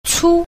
b. 粗 – cū – thô